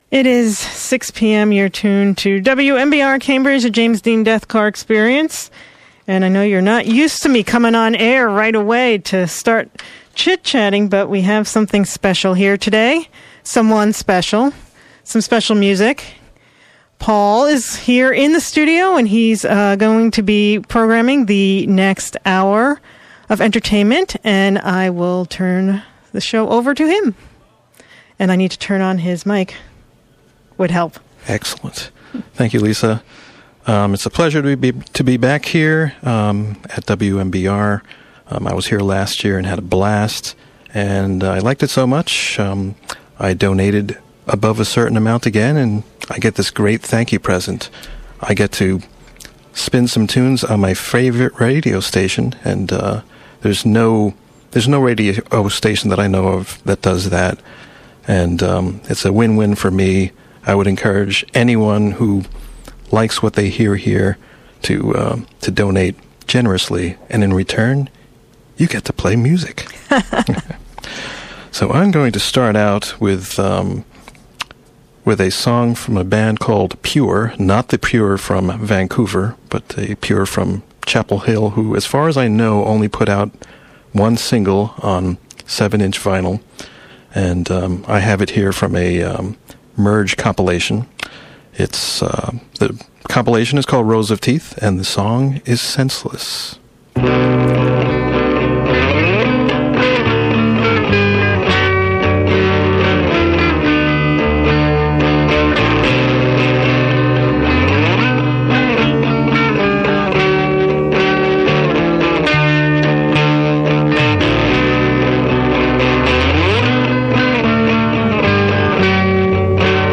Yesterday I had the privilege of guest-hosting for an hour on my favorite radio program The James Dean Deathcar Experience . I tried to avoid an hour of tirelessly punky pop songs, instead breaking the hour up into three distinct sets averaging five songs each. I called them “Dark,” “Dreamy” and, because I just can’t help myself, “Poppy.”